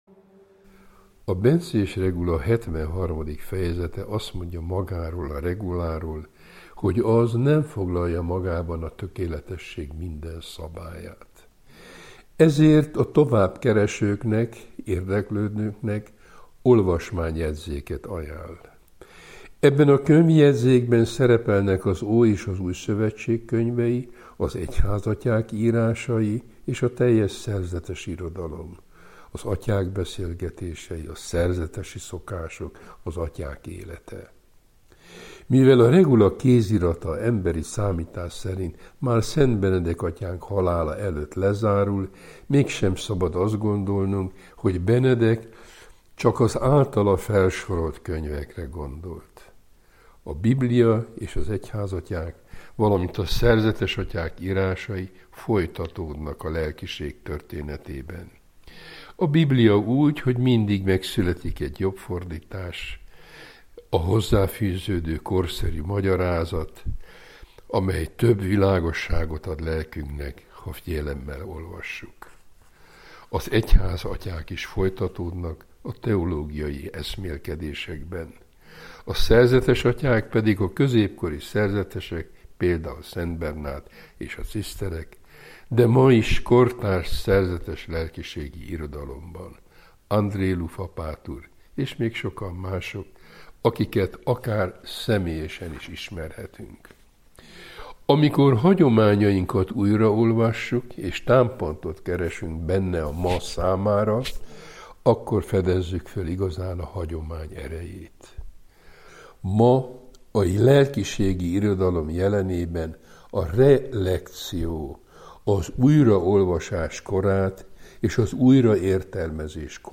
Szent Benedek öröksége Várszegi Asztrik emeritus pannonhalmi főapát tolmácsolásában